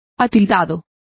Complete with pronunciation of the translation of dapper.